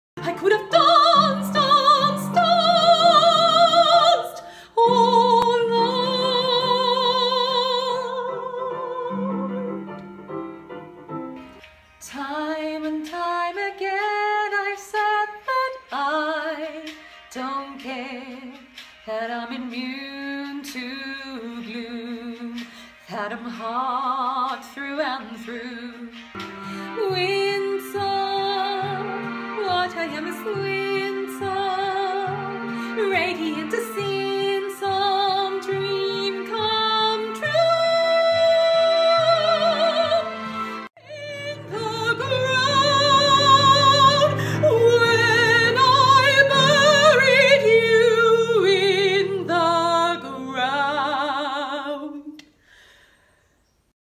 Female
Singing
Musical Theatre - English
0630Musical_Theatre_Song_Reel.mp3